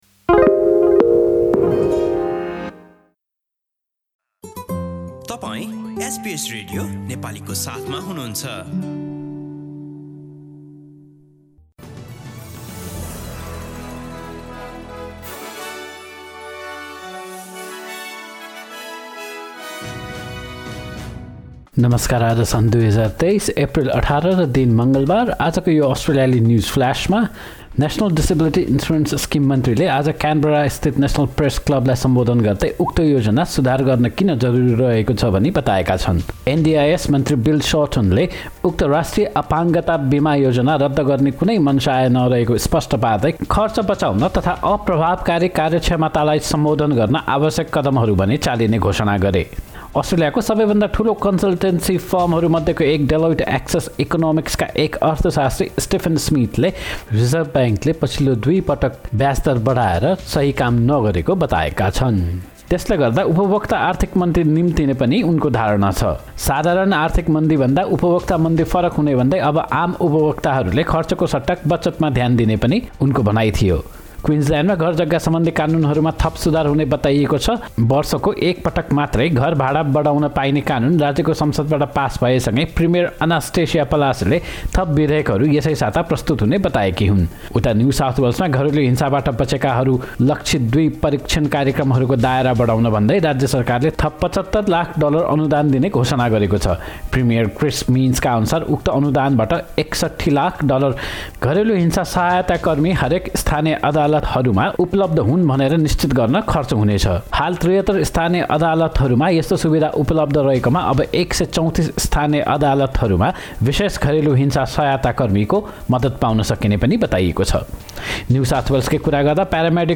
एसबीएस नेपाली अस्ट्रेलिया न्युजफ्लास: मङ्गलवार, १८ एप्रिल २०२३